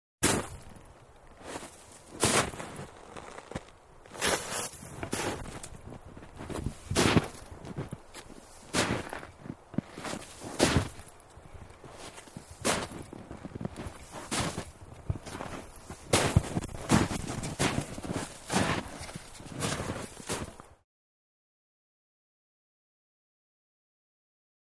Boots In Snow | Sneak On The Lot
Walking In Snow With Ski Boots On.